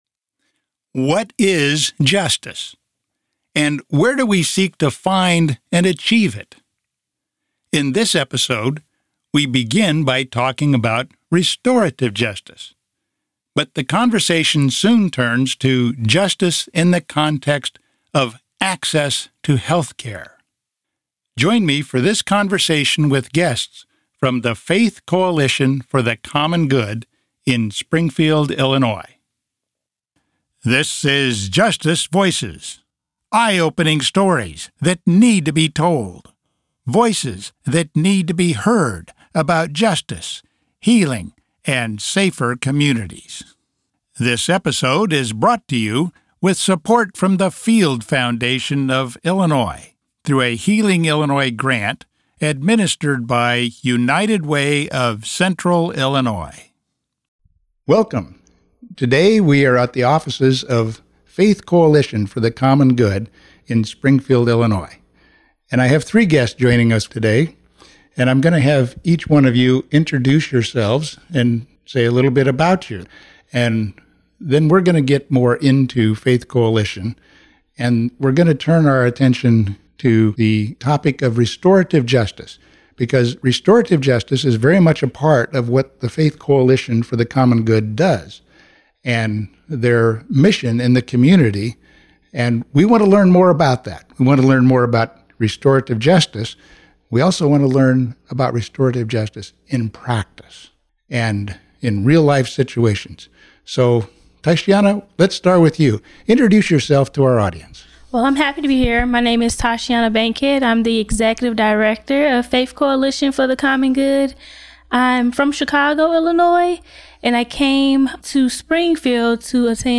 Restorative Justice, justice in healthcare, and racial justice discussed by representatives of the Faith Coalition for the Common Good,